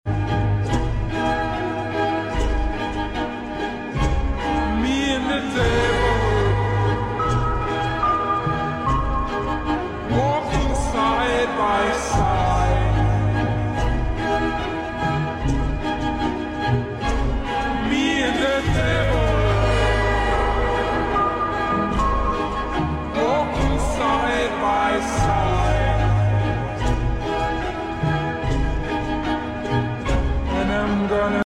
Wireless power bank sound effects free download